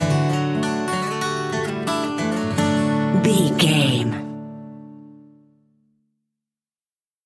Ionian/Major
D
Slow
acoustic guitar
bass guitar
Pop Country
country rock
bluegrass
happy
uplifting
driving
high energy